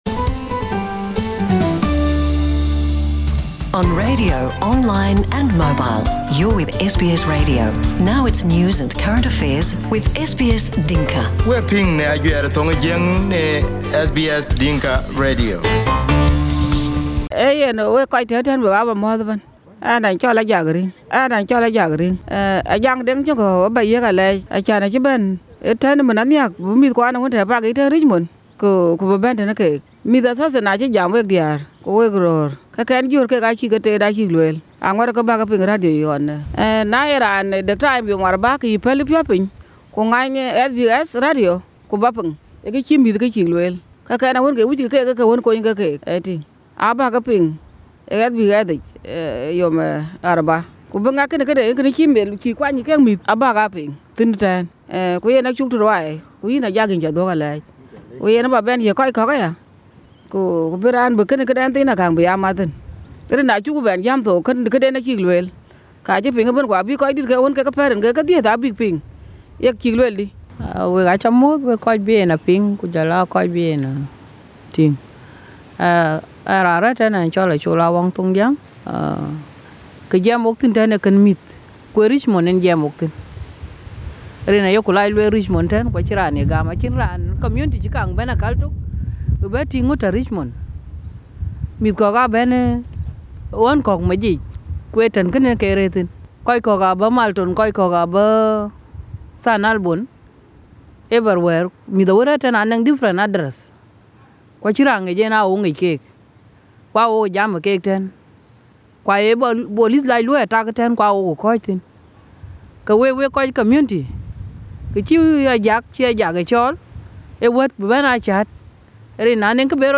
South Sudanese mothers at the public housing in Richmond are calling for community leaders to support ideal youth in most of the public accommodations. In this interview on SBS Dinka, last Saturday; three mothers explained what they have to deal with for the last many years.